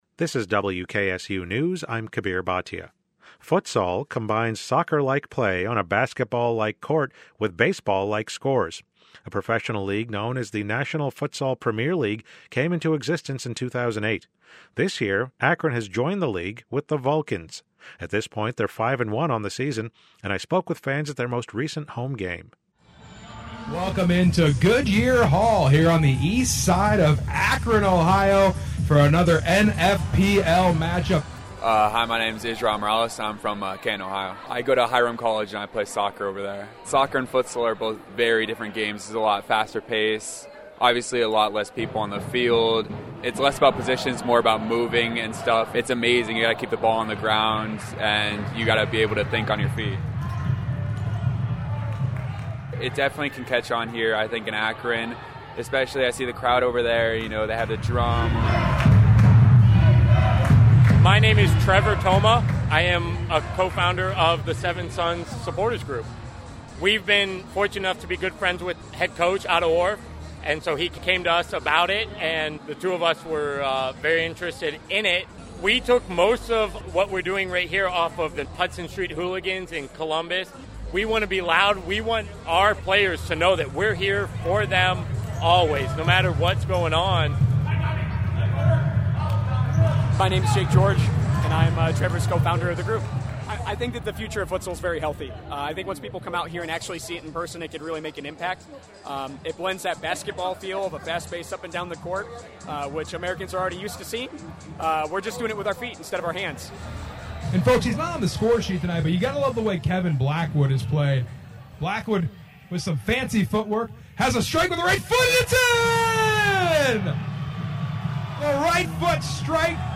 DE-FENSE! DE-FENSE!
akron_vulcans_futsal.mp3